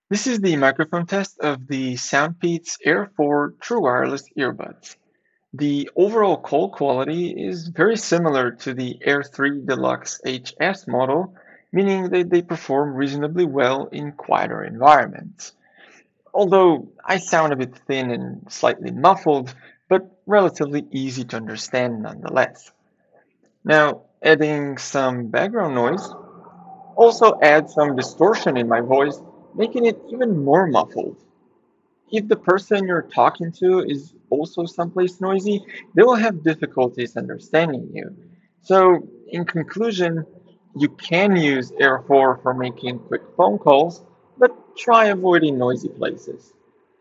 SoundPEATS-Air4-mic-test.mp3